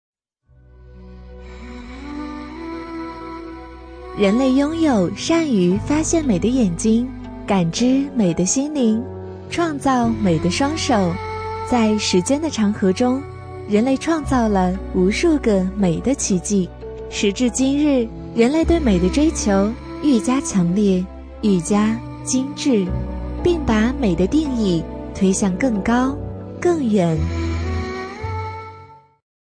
【女57号专题】甜美柔和夜听风格